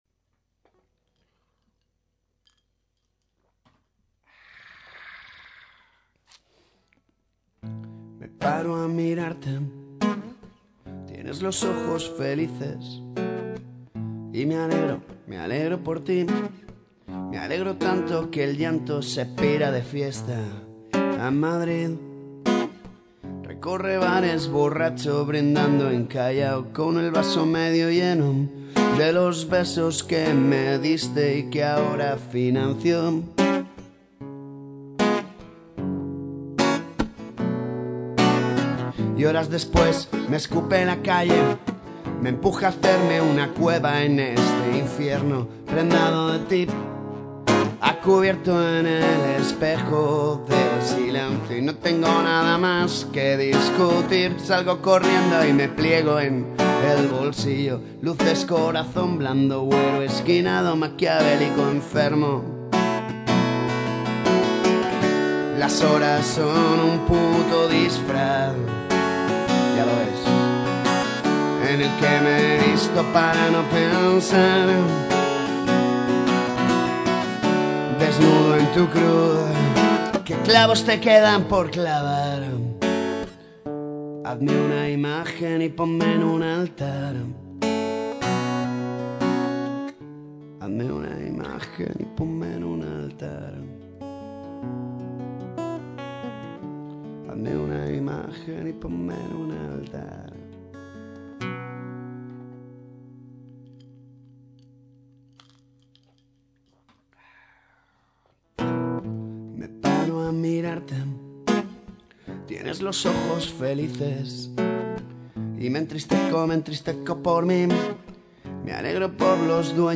2. esas grabaciones son lo más parecido a una maqueta, jejeje. Una mesilla de mezclas y el ordenador.